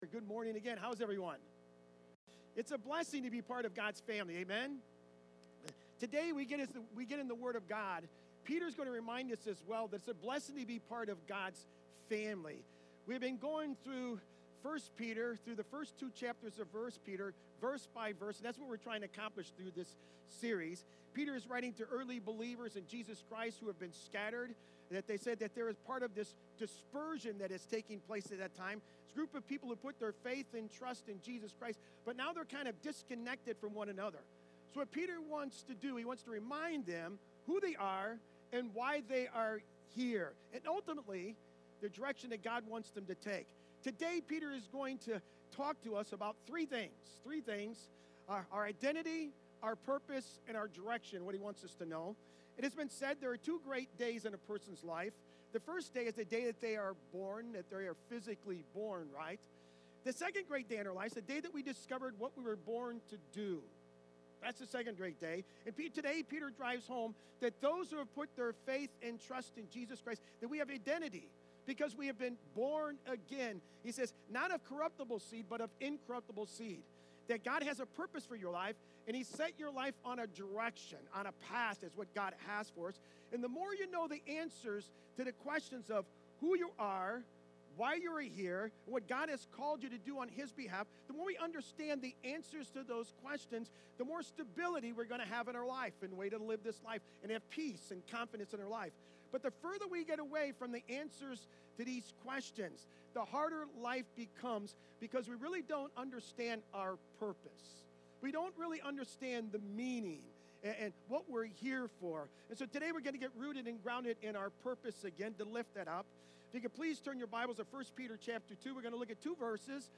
Sermons
2-9-25-sermon.mp3